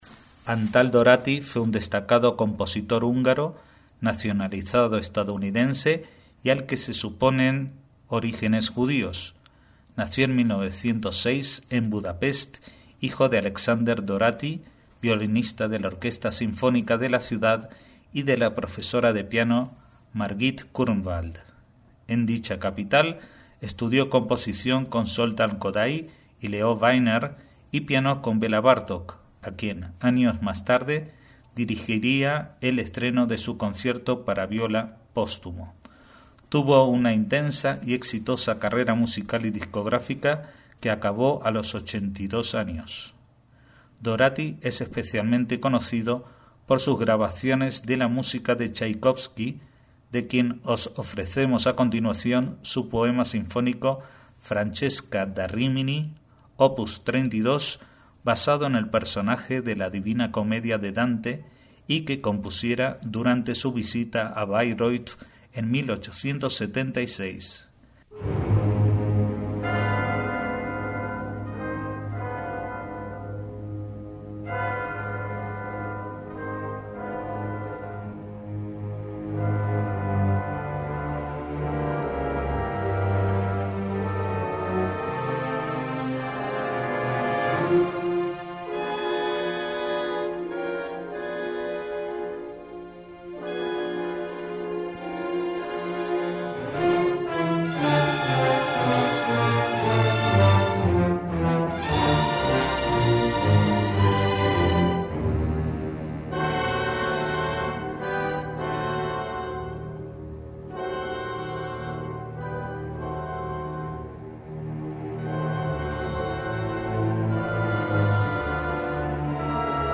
MÚSICA CLÁSICA - Antal Dorati (1908 – 1988) fue el director de orquesta que más grabaciones ha realizado en el siglo XX (más de 600) y del que no todo el mundo conoce su origen judío.